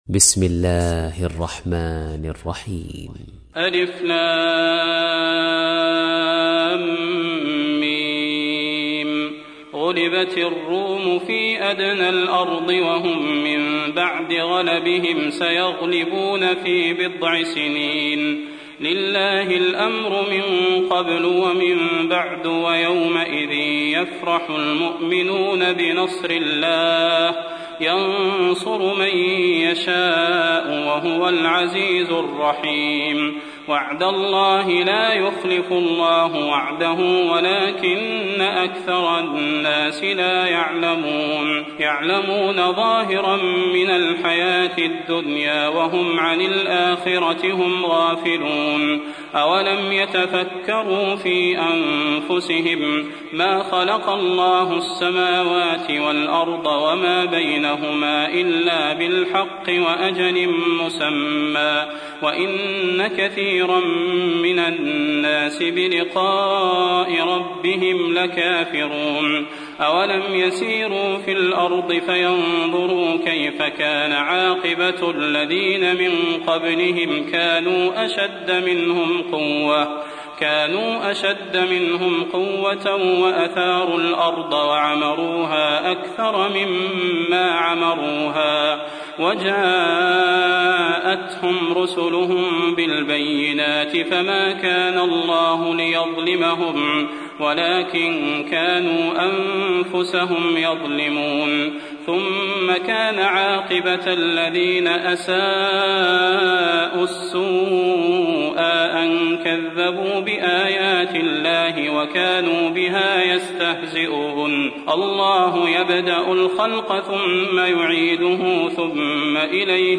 تحميل : 30. سورة الروم / القارئ صلاح البدير / القرآن الكريم / موقع يا حسين